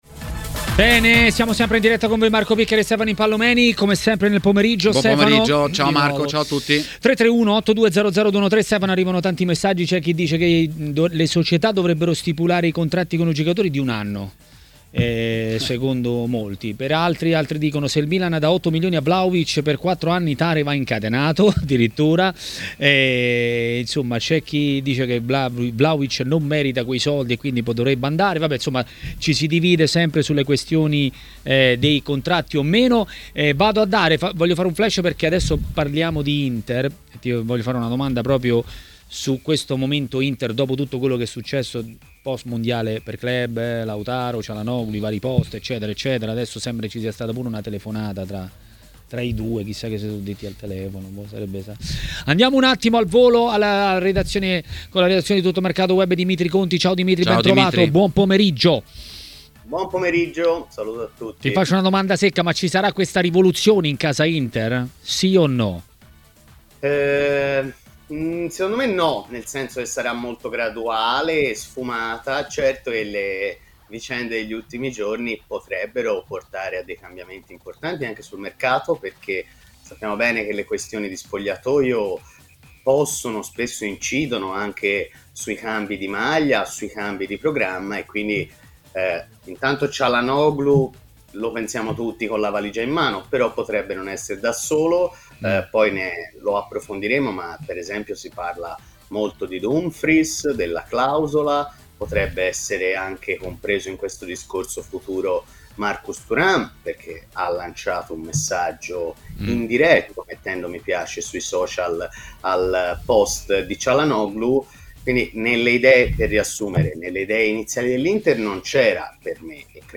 A parlare di Inter e non solo a TMW Radio, durante Maracanà, è stato l'ex calciatore Fausto Pizzi.